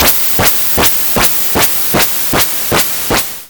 Chemical lab idle effect.wav
chemical_lab_idle_effect.wav